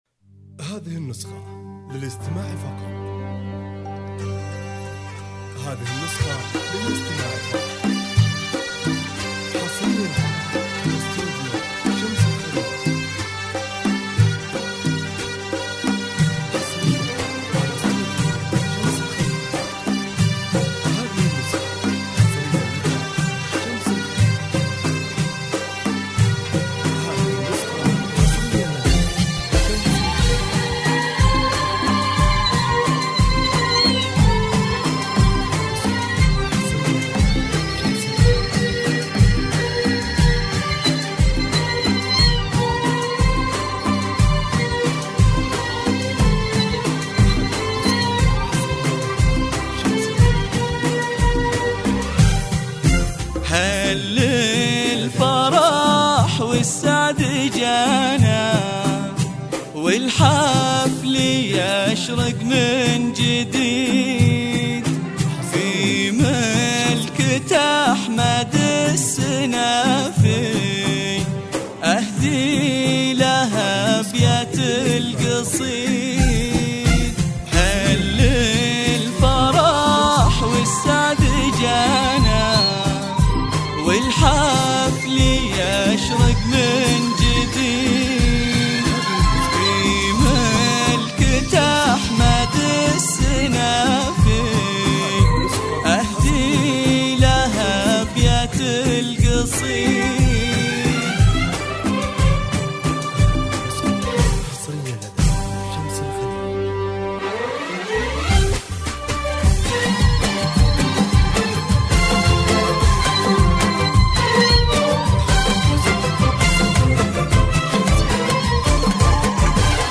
شيلات